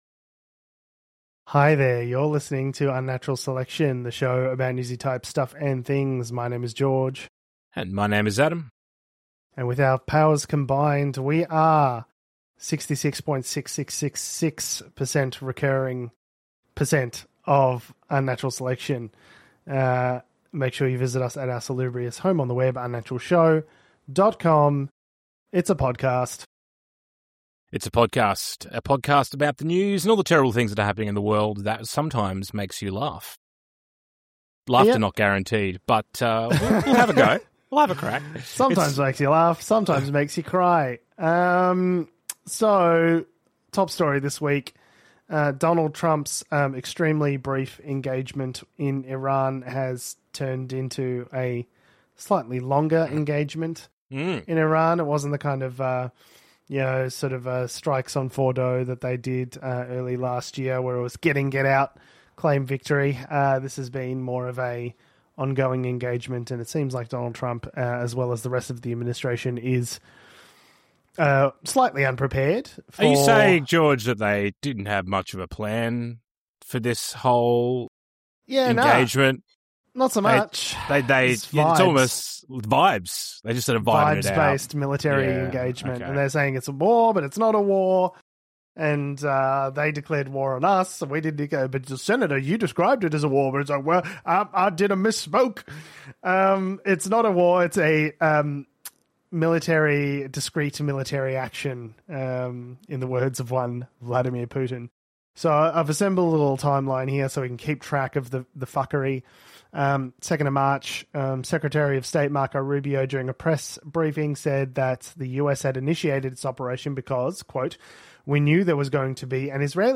Unnatural Selection is a weekly comedy podcast about news and politics. Each week the guys take a few stories from the week's news, and bring a fresh and funny new take that you may not have considered. They cover Australian and American news as well as some stores from around the world.